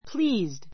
pleased A2 plíːzd プ り ー ズド 形容詞 ❶ 満足した, 喜んだ, 気に入って with a pleased look with a pleased look うれしそうな顔つきで He looks pleased.